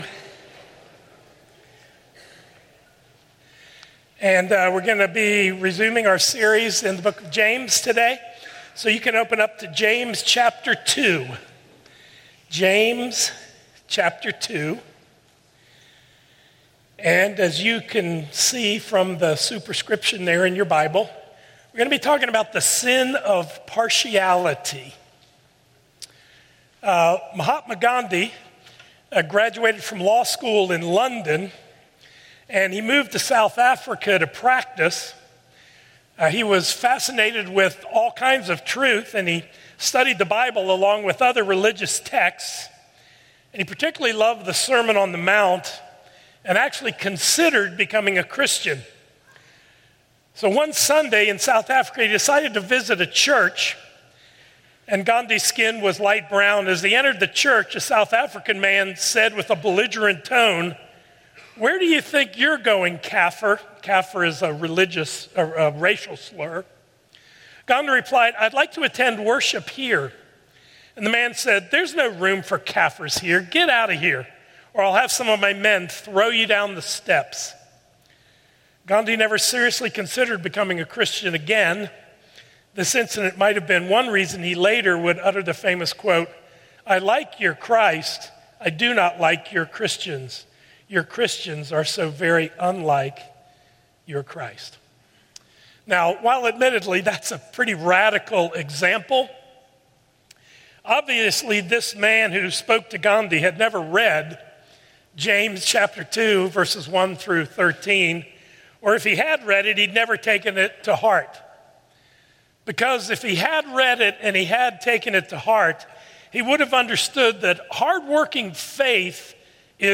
A message from the series "New Life in Jesus."